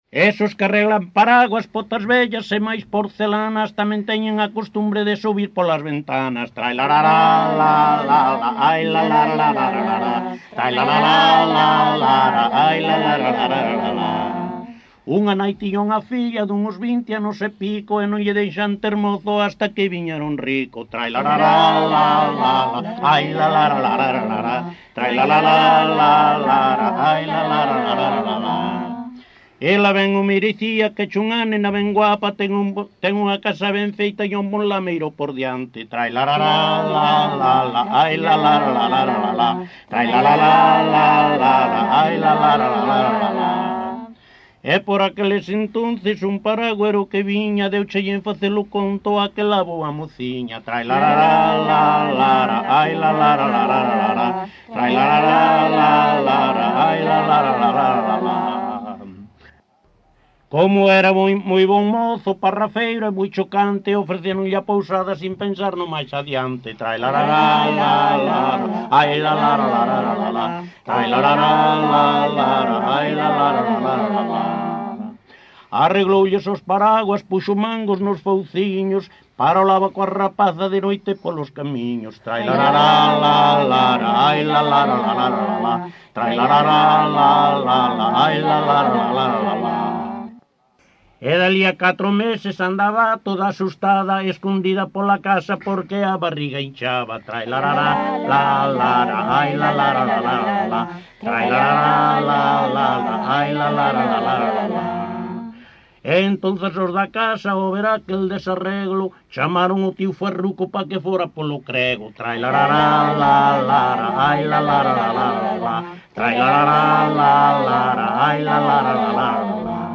Tipo de rexistro: Musical
LITERATURA E DITOS POPULARES > Cantos narrativos
Soporte orixinal: Casete
Datos musicais Refrán
Instrumentación: Voz
Instrumentos: Voces femininas, Voz masculina
Peza recollida no centro de atención á terceira idade de Ribadeo.